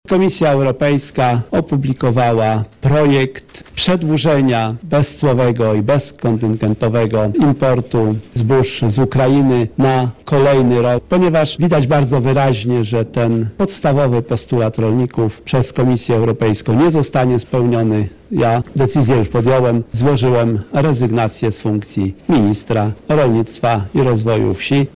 Henryk Kowalczyk– mówi Henryk Kowalczyk, wicepremier i minister rolnictwa i rozwoju wsi